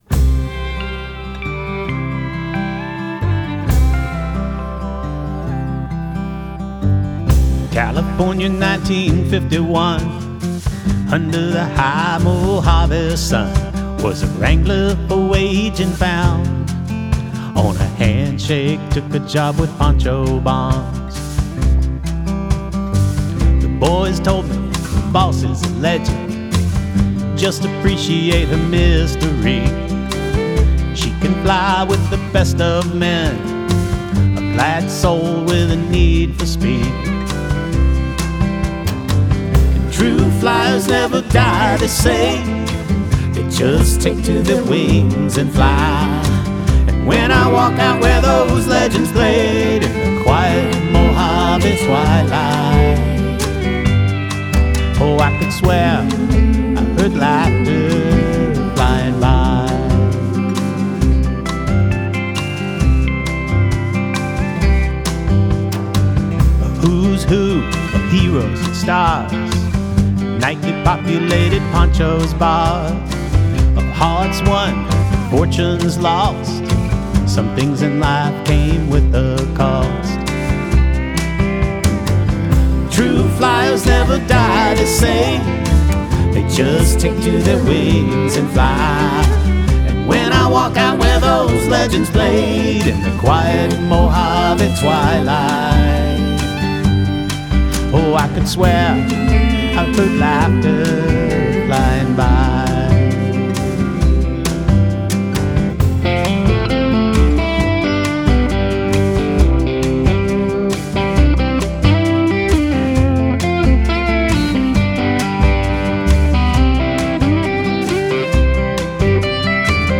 vocals, Telecaster guitar
Pro Tools home recording, files sent remotely.